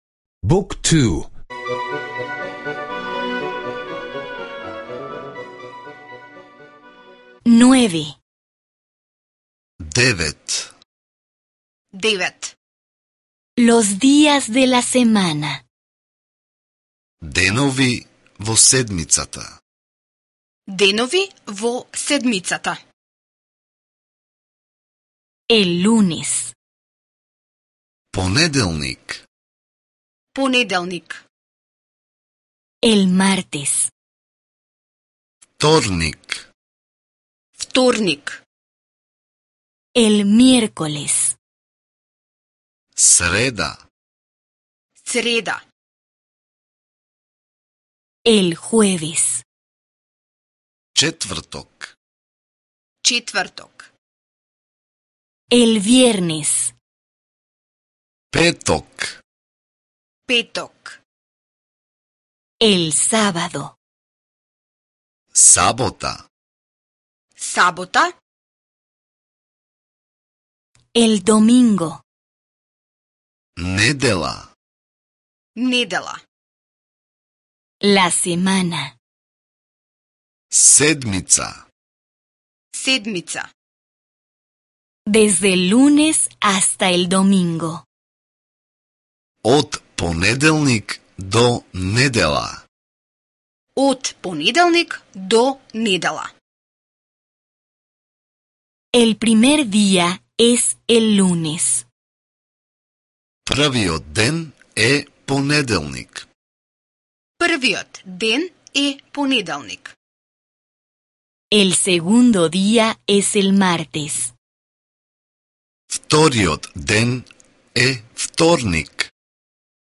Curso de audio en macedonio (descarga gratis)